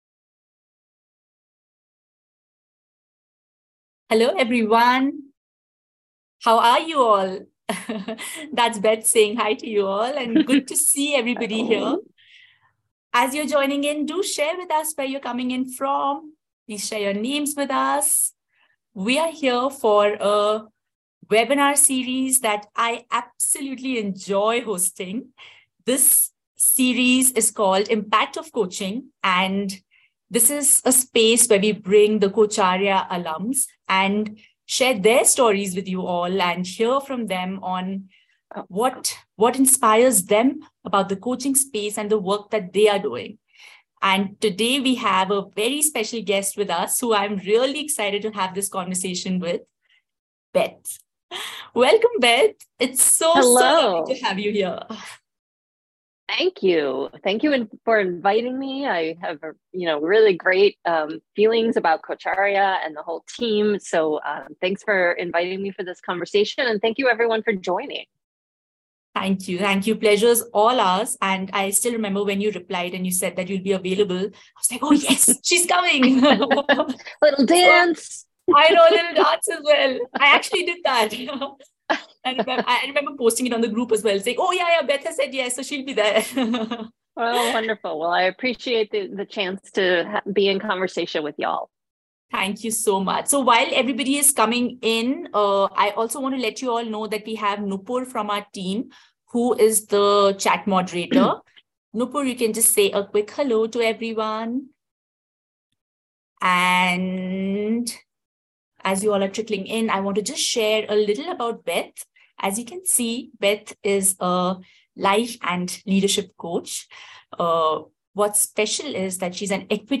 This session was recorded as part of the ICW 2024 Celebration